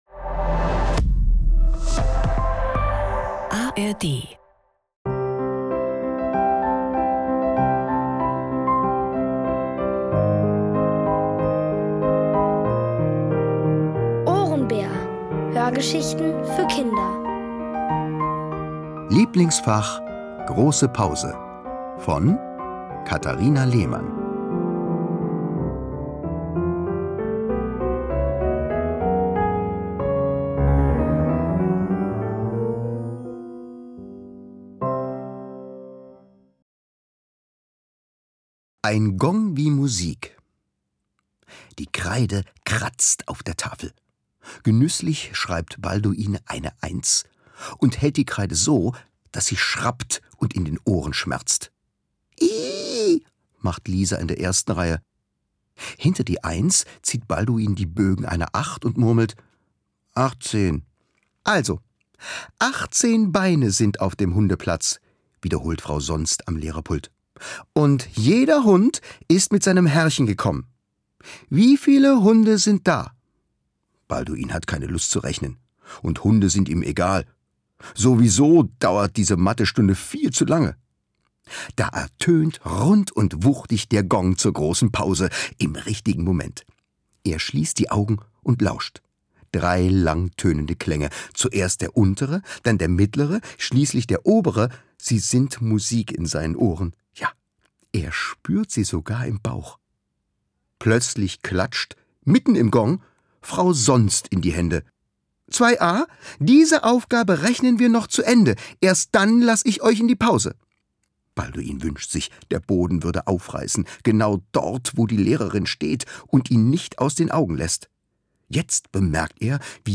Alle 6 Folgen der OHRENBÄR-Hörgeschichte: Lieblingsfach: große Pause von Katharina Lehmann. Es liest: Thomas Nicolai.